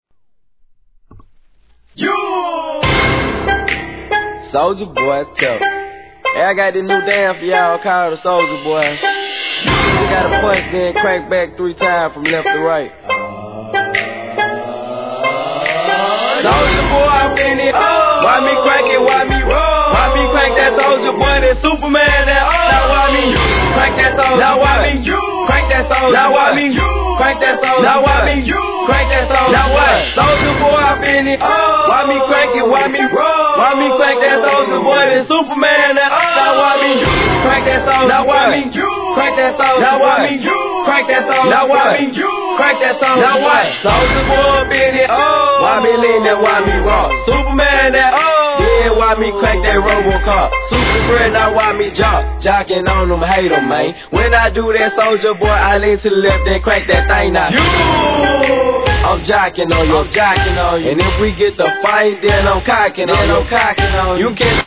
HIP HOP/R&B
SOUTHファンはたまらなく体がうずく様なダウナーBEATでのダンスネタ!!!